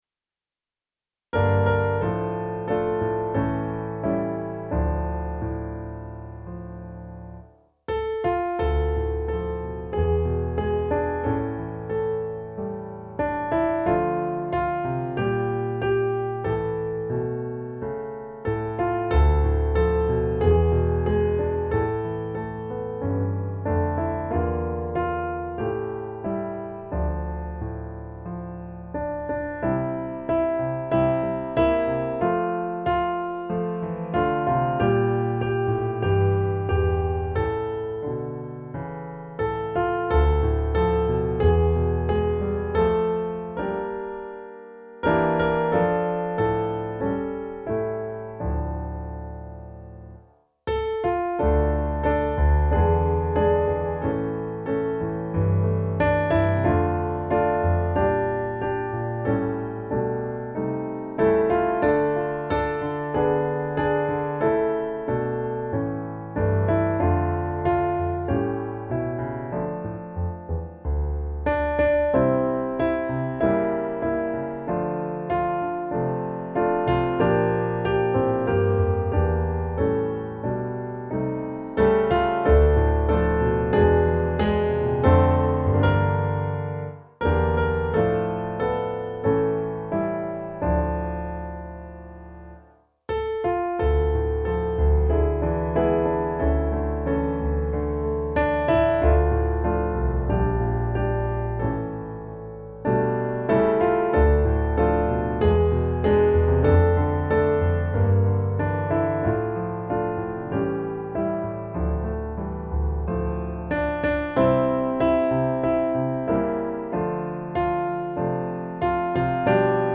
musikbakgrund
Musikbakgrund Psalm